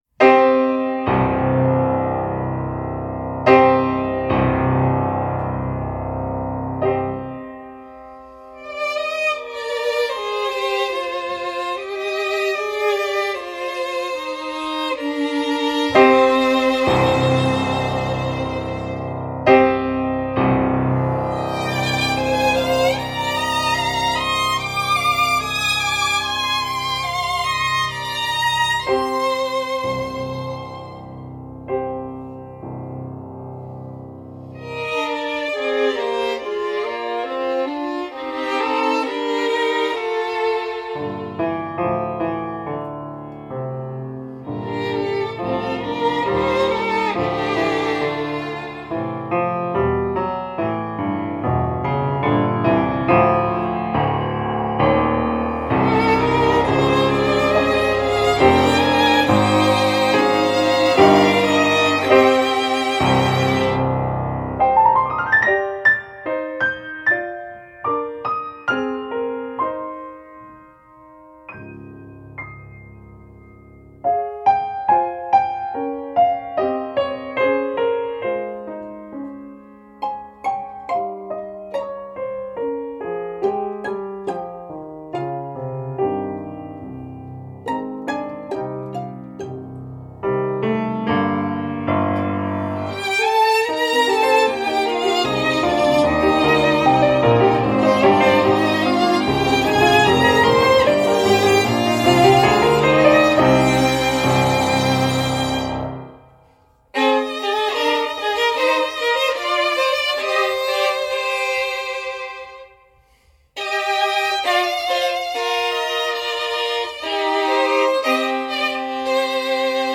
Music for Piano and Two Violins Read through done in a recording studio rather than a classroom.
Music for Piano and Two Violins.mp3